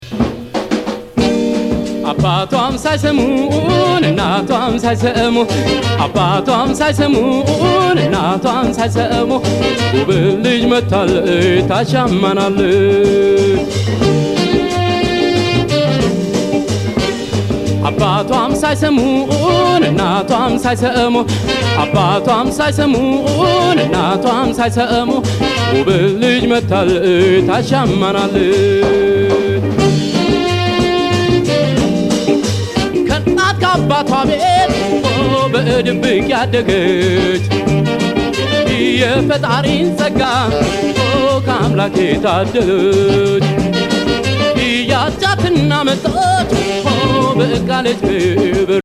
集!魅惑のソウル~ファンク！